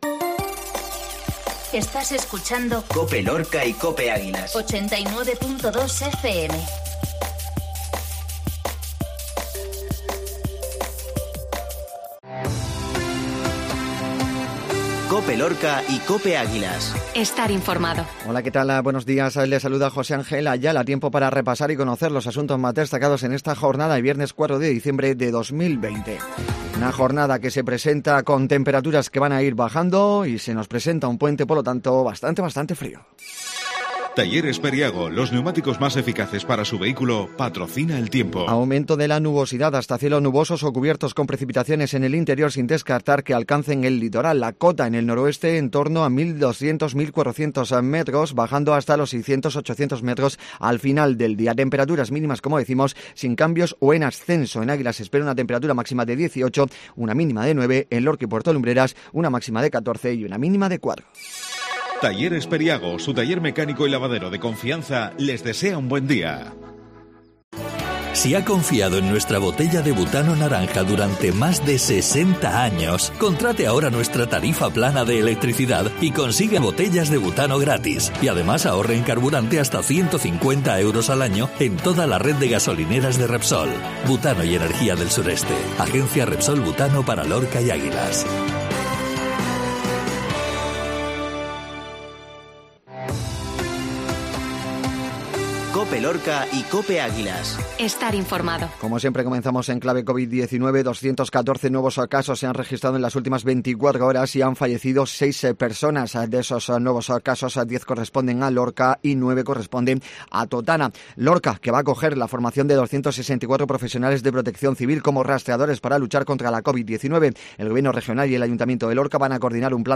INFORMATIVO MATINA VIERNES COPE